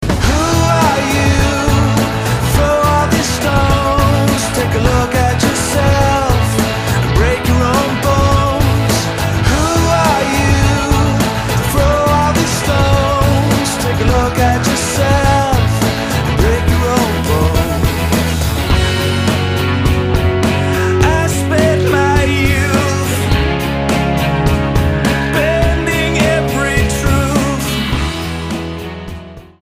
STYLE: Rock
bass
keyboard
vocals